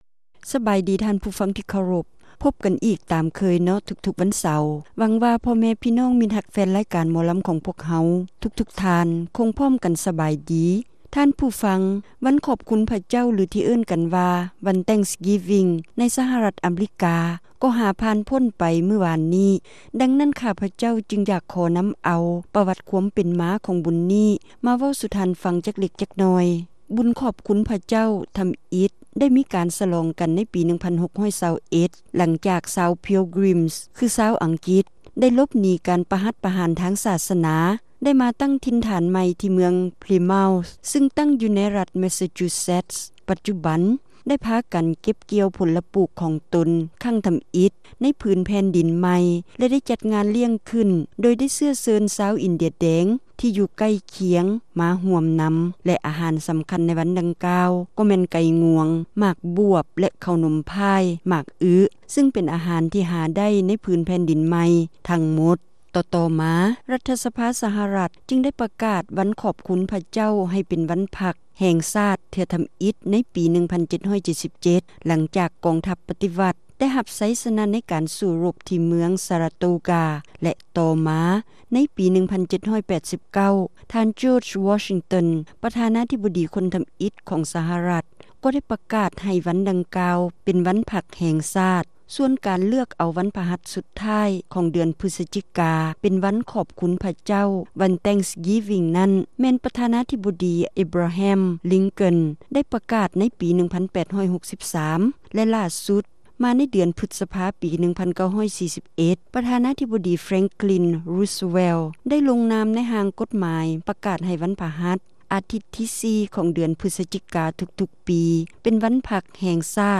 ຣາຍການໜໍລຳ ປະຈຳສັປະດາ ວັນທີ 23 ເດືອນ ພຶສຈິກາ ປີ 2007